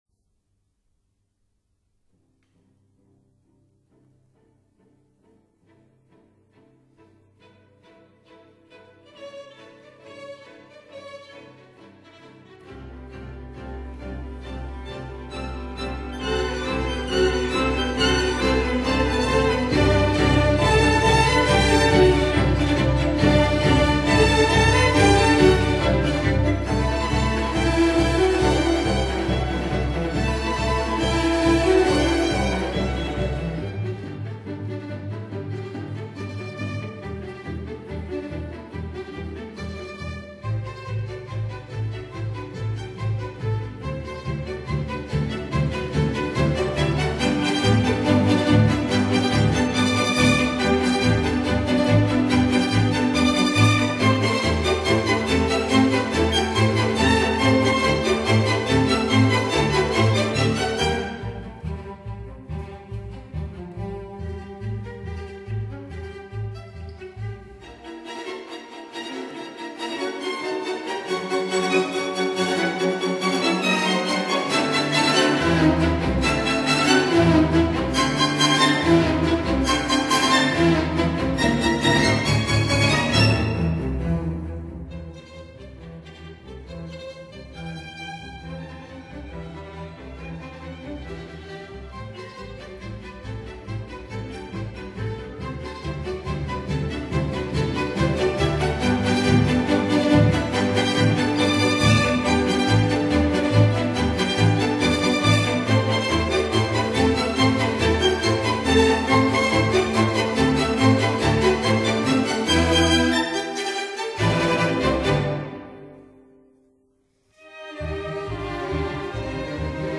Tempo di marcia, molto ritmico    [0:04:32.00]